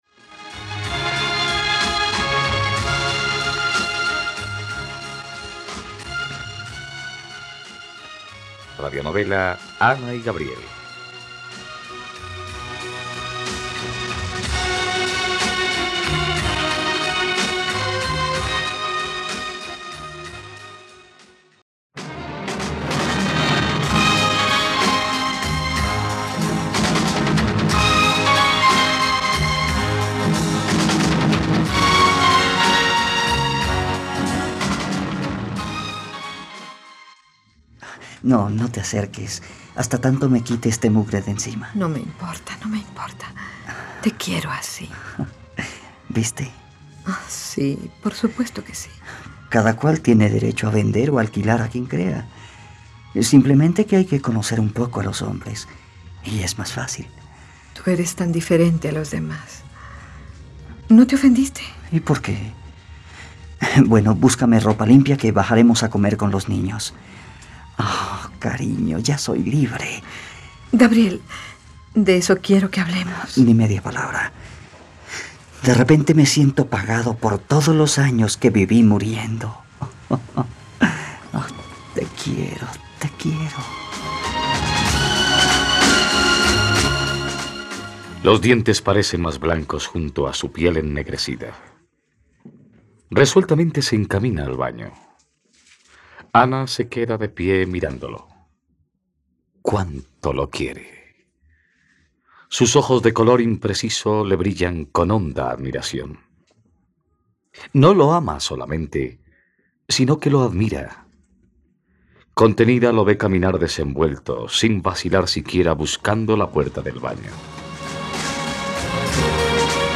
..Radionovela. Escucha ahora el capítulo 124 de la historia de amor de Ana y Gabriel en la plataforma de streaming de los colombianos: RTVCPlay.